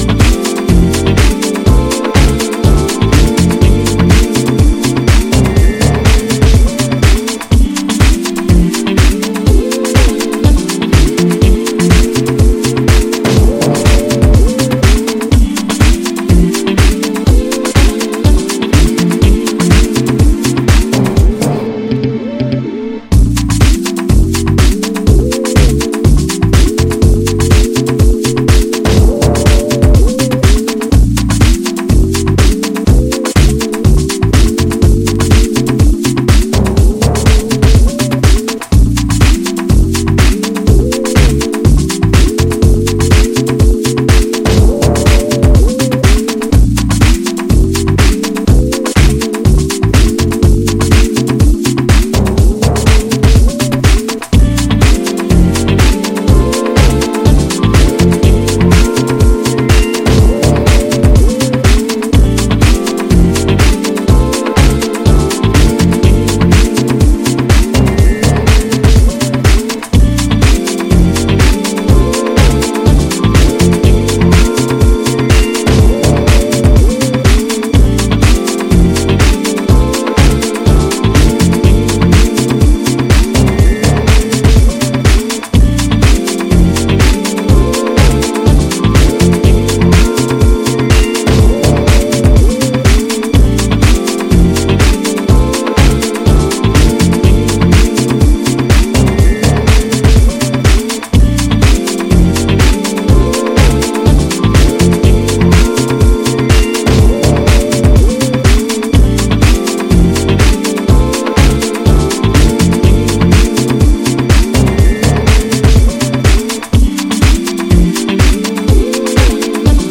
【12"INCH】(レコード)
ジャンル(スタイル) DEEP HOUSE